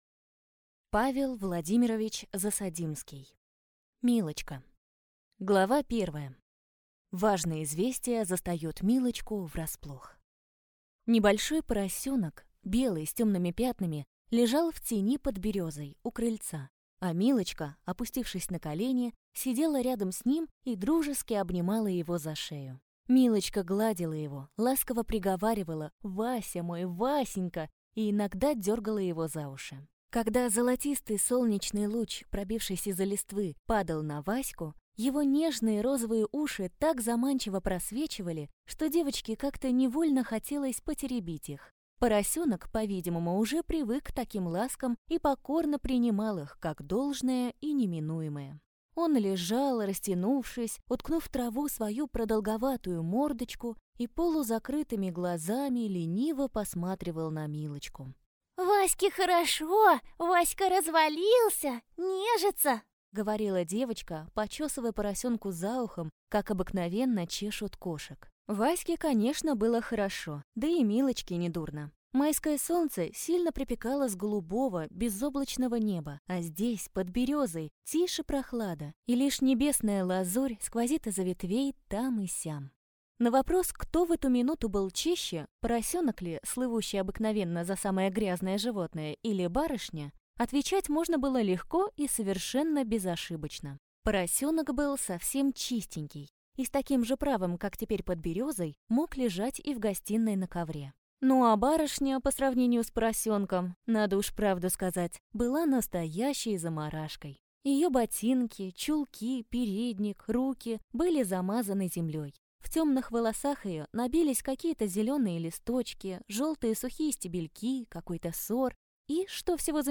Аудиокнига Милочка | Библиотека аудиокниг